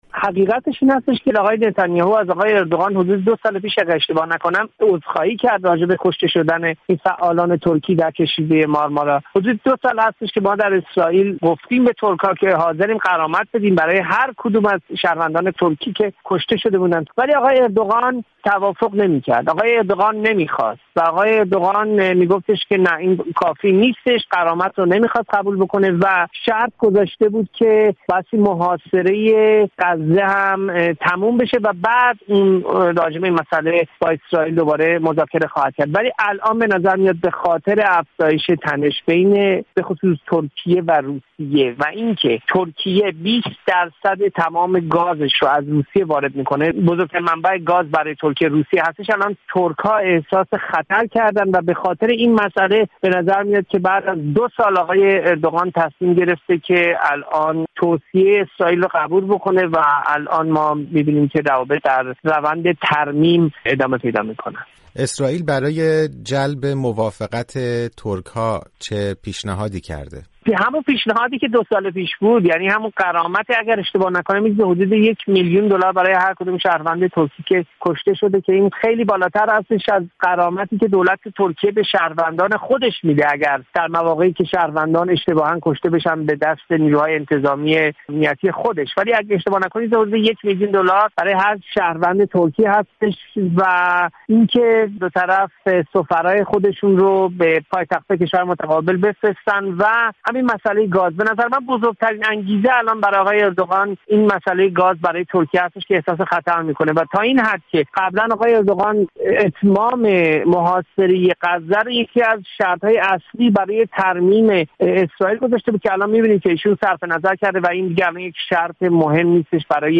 در گفت‌وگو